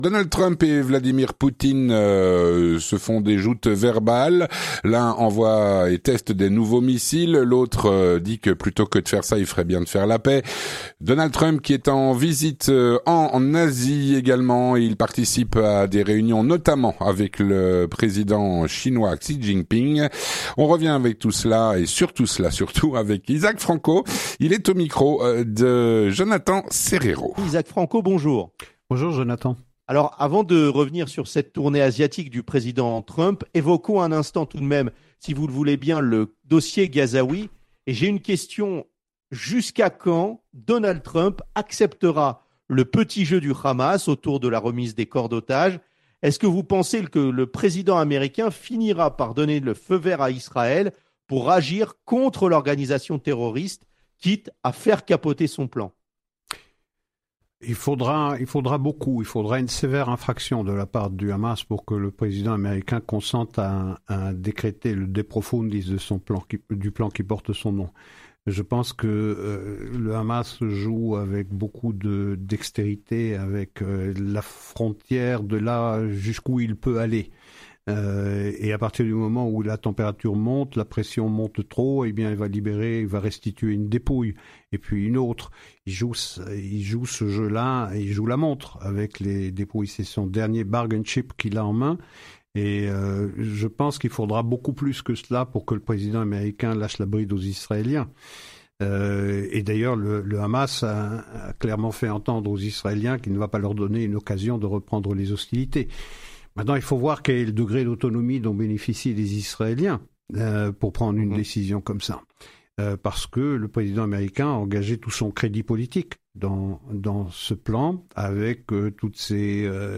L'entretien du 18H - Guerre en Ukraine, Rencontrte Trump Xi-jiping, Restitution des corps d'otages.